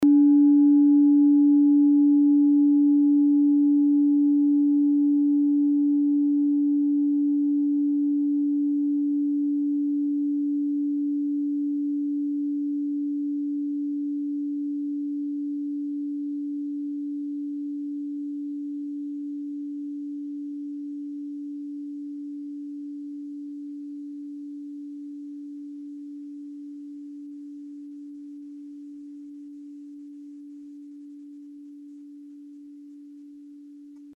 Klangschalen-Typ: Bengalen und Tibet
Klangschale 6 im Set 5
Klangschale Nr.6
(Aufgenommen mit dem Filzklöppel/Gummischlegel)
klangschale-set-5-6.mp3